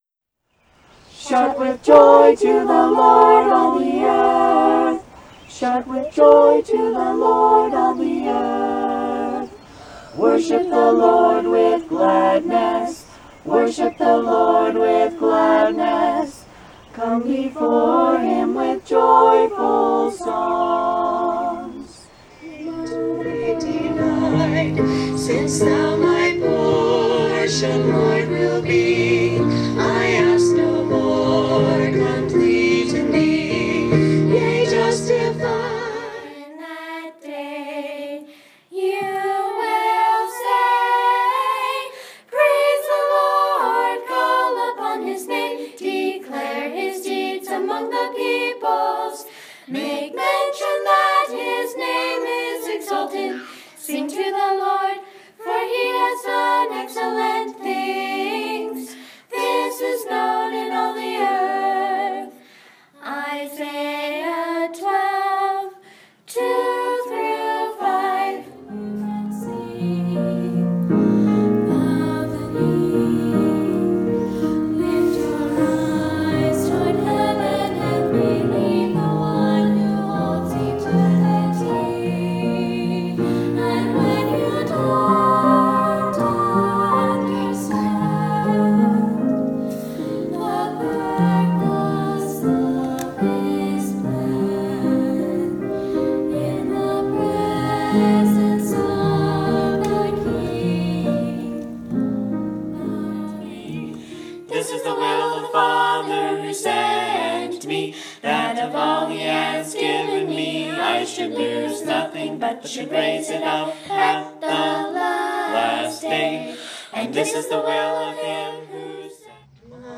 Below you’ll find a sampling of our vocal repertoire, which includes a cappella and live accompanied hymns, Scripture songs (some original), and Sacred Harp styles.
Vocal Sampler (scroll down for videos):